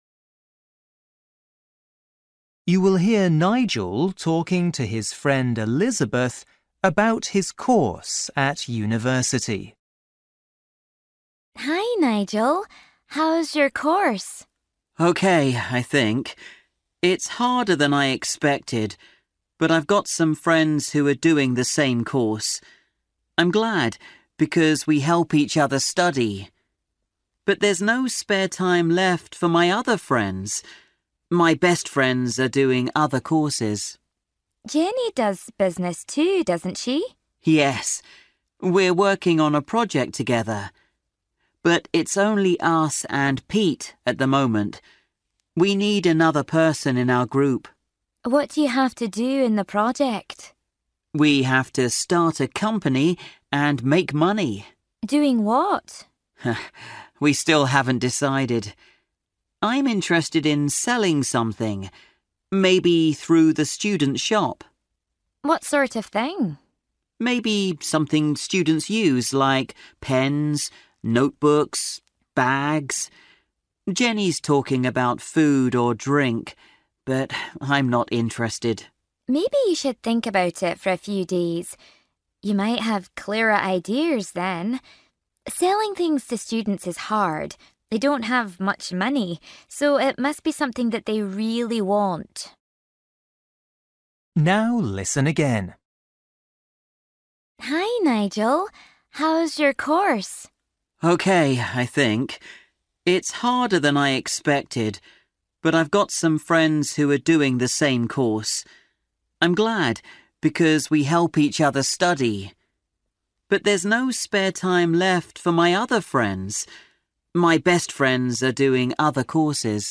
Bài tập trắc nghiệm luyện nghe tiếng Anh trình độ sơ trung cấp – Nghe một cuộc trò chuyện dài phần 11